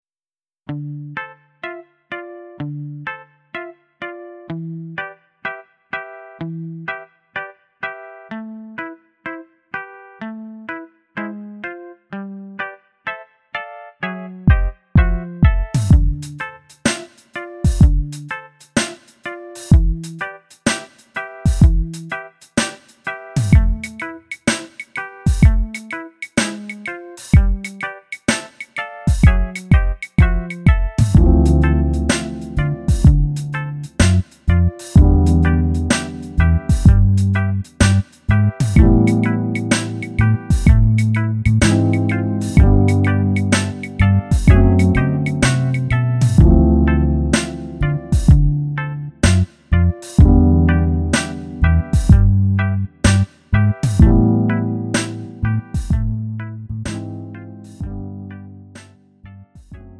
음정 원키 3:53
장르 가요 구분 Lite MR